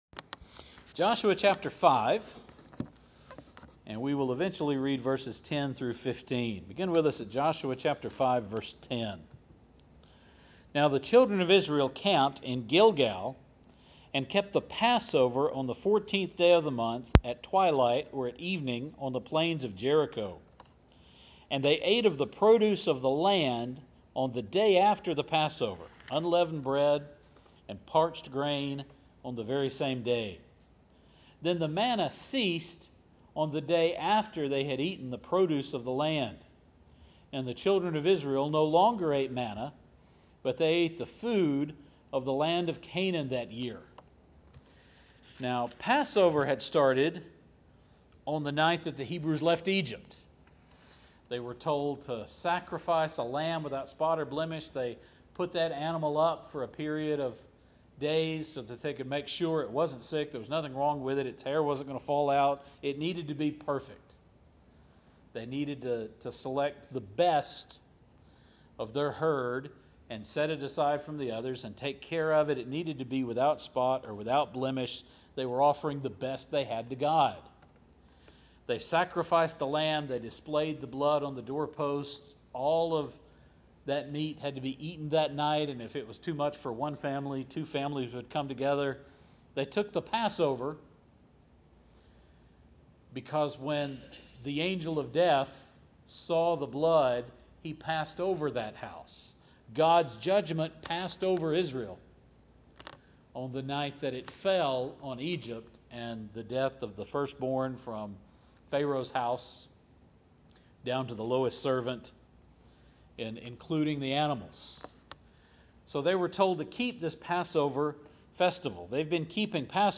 This is the Sunday morning sermon from July 8th, 2018.